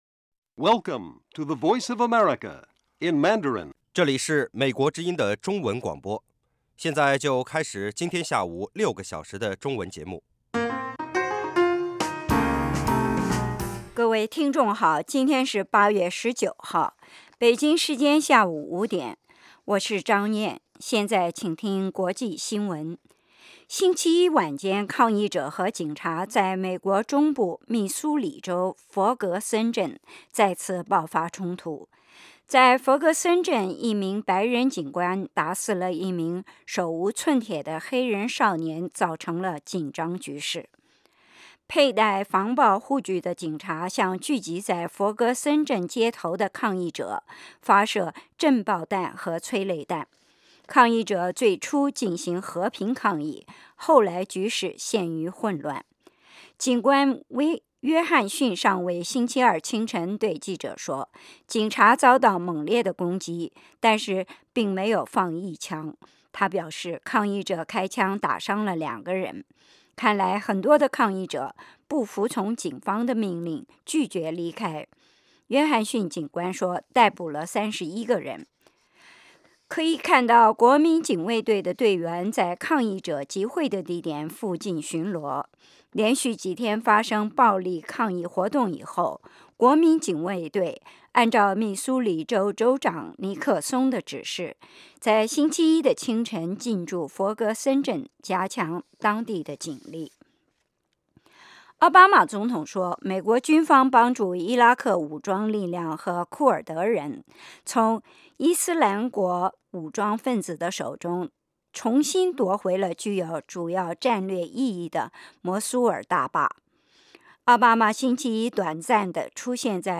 晚5-6点广播节目